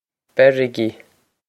Beirigí Ber-ih-gee
This is an approximate phonetic pronunciation of the phrase.